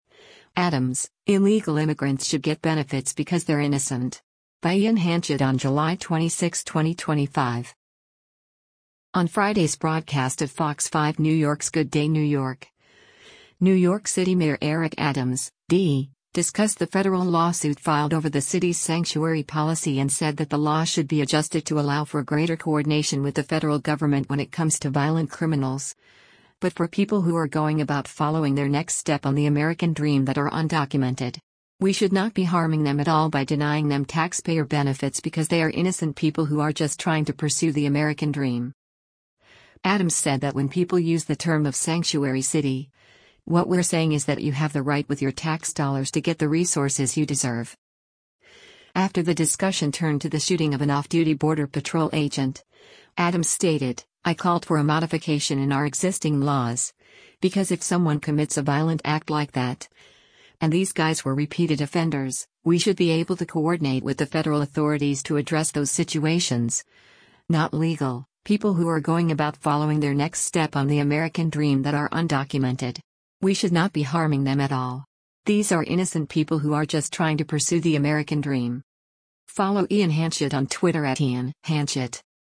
On Friday’s broadcast of Fox 5 New York’s “Good Day New York,” New York City Mayor Eric Adams (D) discussed the federal lawsuit filed over the city’s sanctuary policy and said that the law should be adjusted to allow for greater coordination with the federal government when it comes to violent criminals, but for people “who are going about following their next step on the American Dream that are undocumented. We should not be harming them at all” by denying them taxpayer benefits because they “are innocent people who are just trying to pursue the American Dream.”